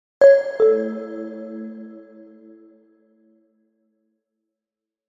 Звуки уведомлений о сообщениях
Звук нового письма в электронной почте